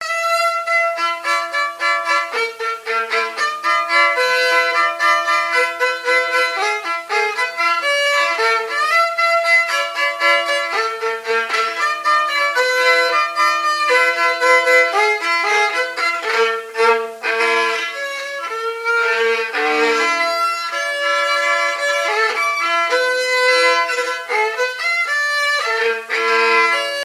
Fonction d'après l'analyste gestuel : à marcher ;
Usage d'après l'analyste circonstance : fiançaille, noce ;
Catégorie Pièce musicale inédite